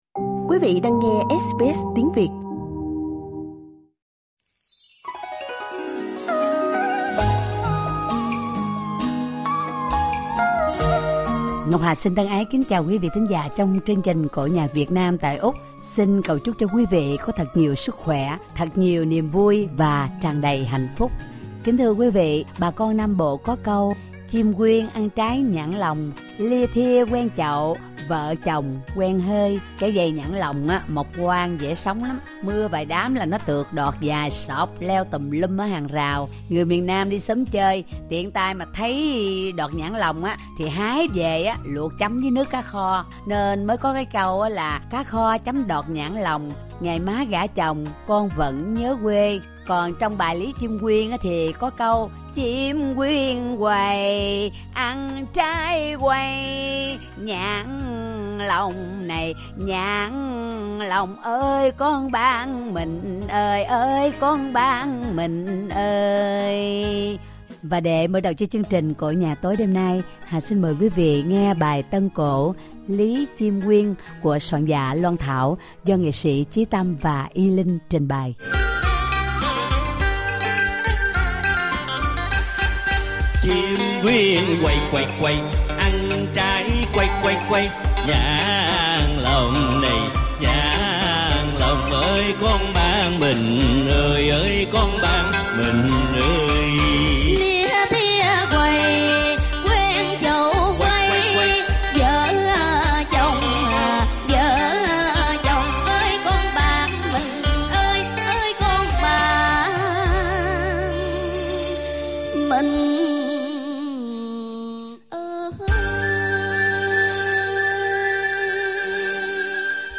tân cổ